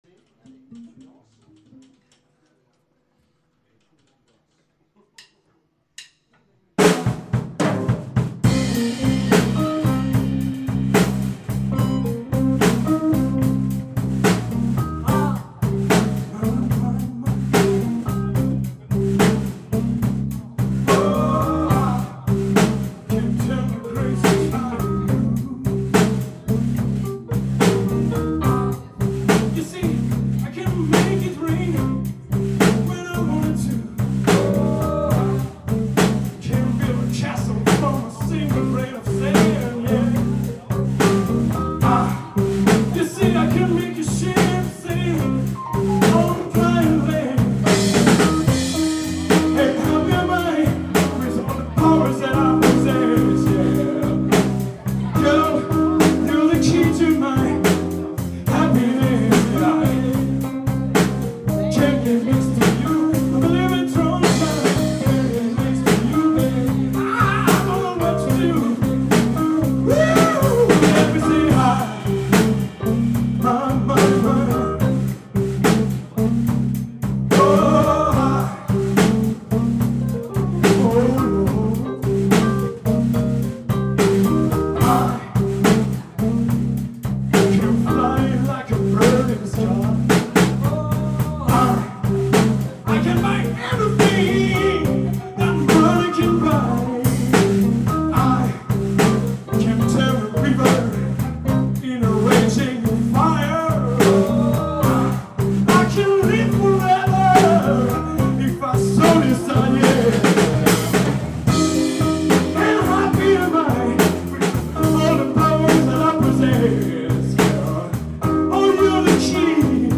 Morceau Blues  en C mineur 7